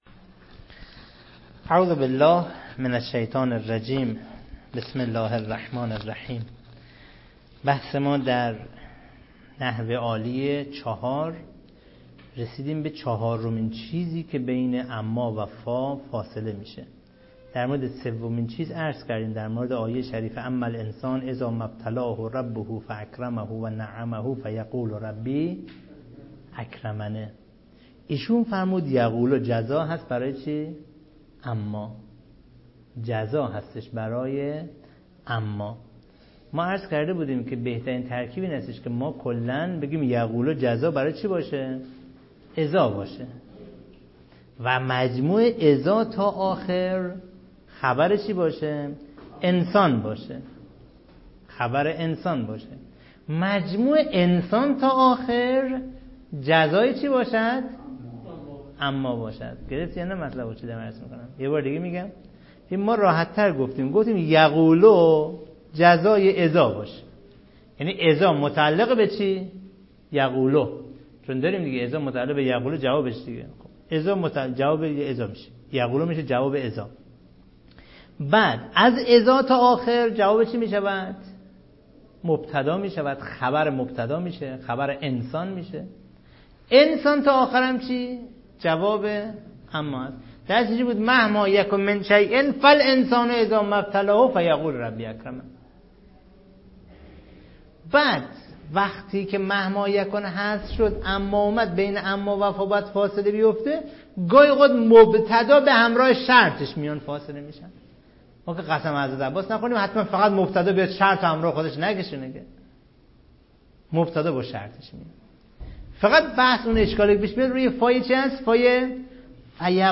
صوت دروس حوزوی- صُدا